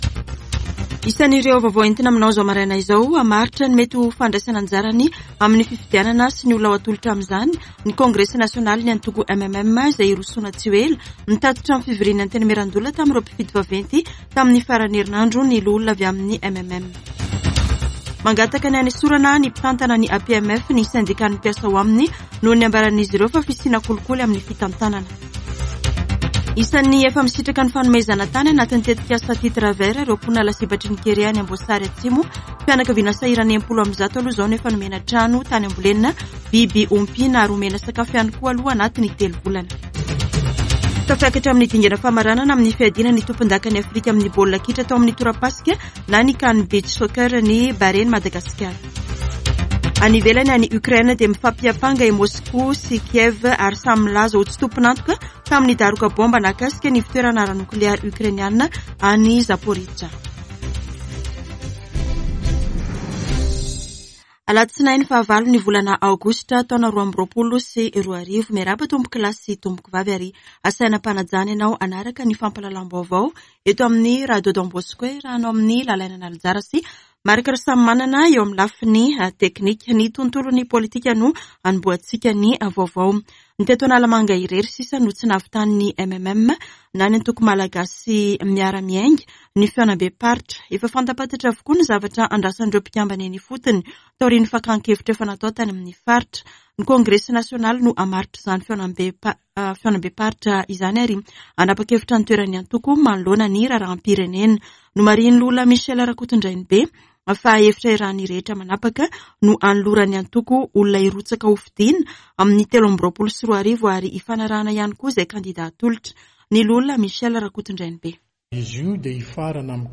[Vaovao maraina] Alatsinainy 08 aogositra 2022